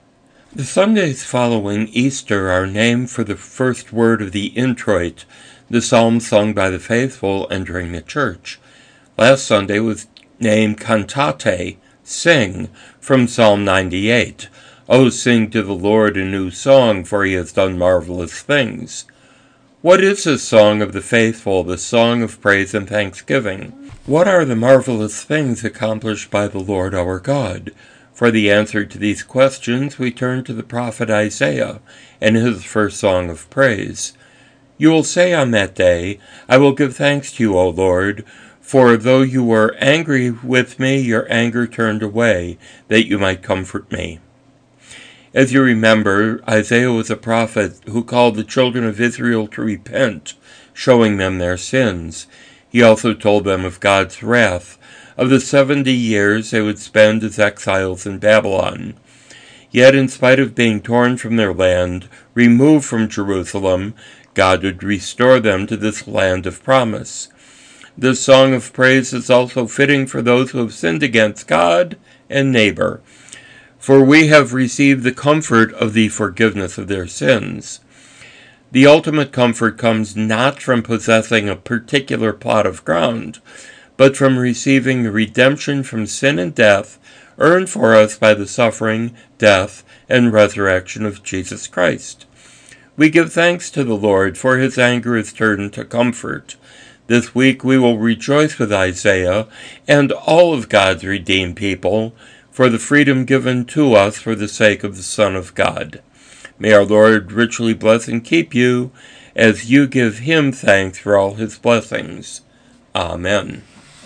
Daily Moments of Meditation devotions as heard each weekday on KJOE FM 106.1 in Slayton.